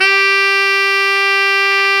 Index of /90_sSampleCDs/Roland L-CD702/VOL-2/SAX_Tenor mf&ff/SAX_Tenor ff
SAX TENORF0M.wav